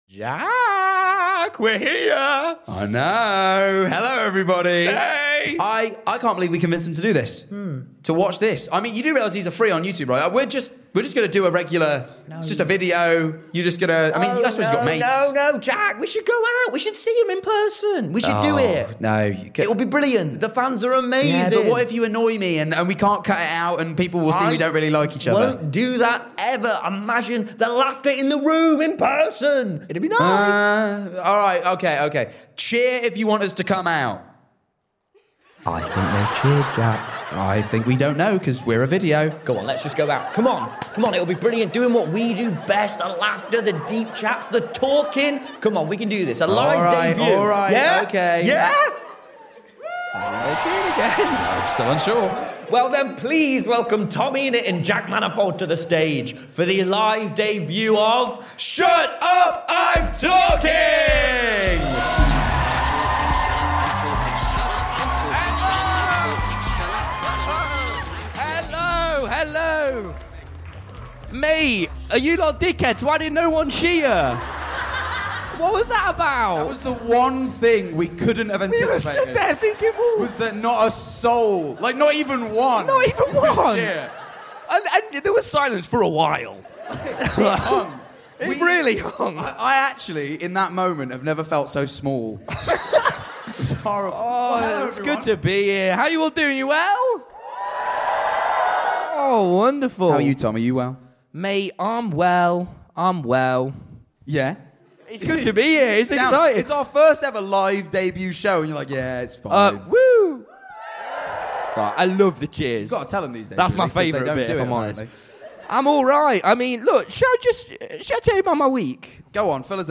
The first ever Shut Up I’m Talking Podcast LIVESHOW!